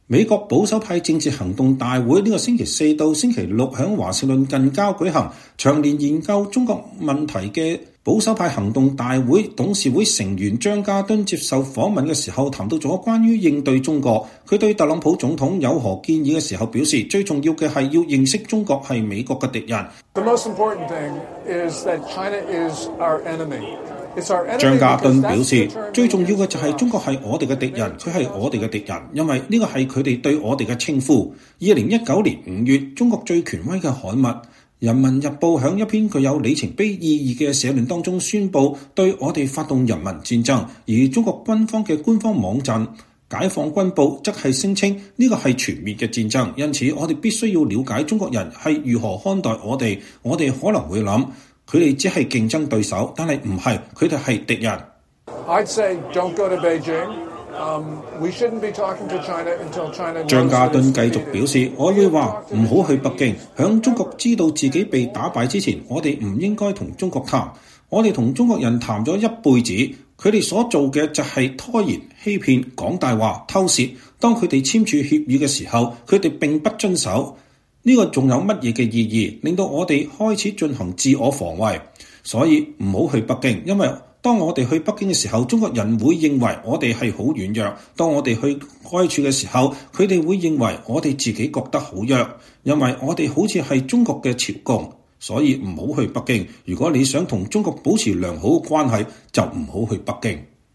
美國保守派政治行動大會本週四到週六在華盛頓近郊舉行。長年研究中共問題的CPAC董事會成員章家敦接受記者訪問時談到關於應對中國，他對特朗普總統有何建議時表示，最重要的是要認識中國是美國的敵人。